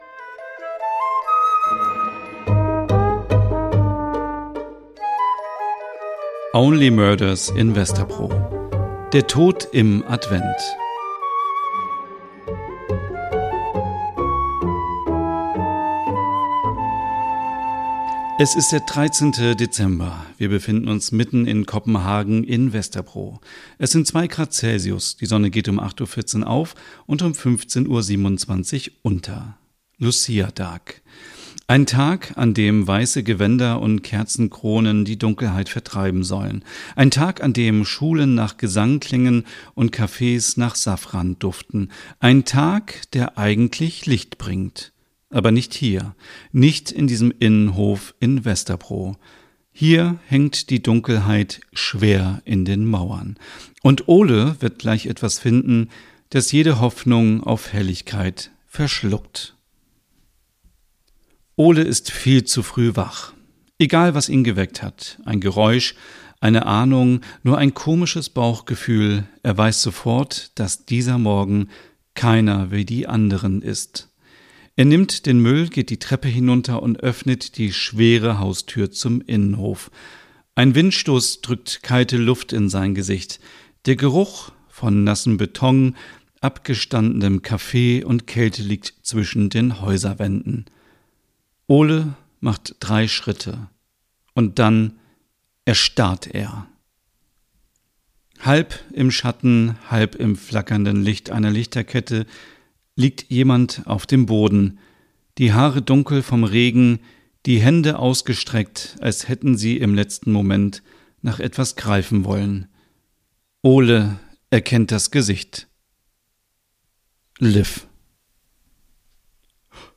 Dann entsteht Only Murders in Vesterbro: ein weihnachtliches Crime-Hörspiel voller nordischer Atmosphäre, schräger Charaktere und warmem Erzähler-Ton.